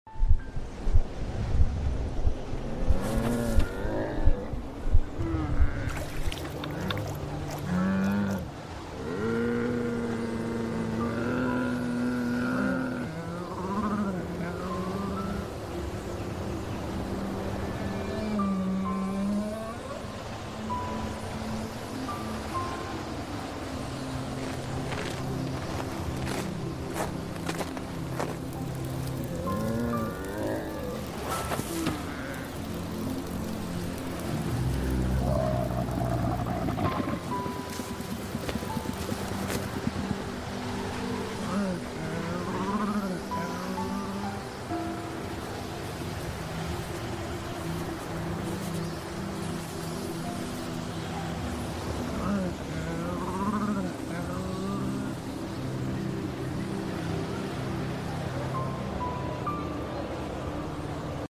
white camel rescue asmr treatment sound effects free download